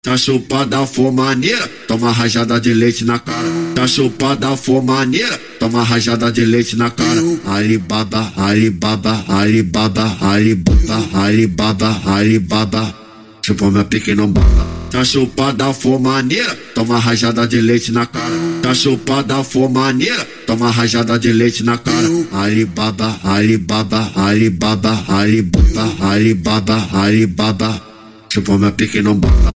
Acapellas de Funk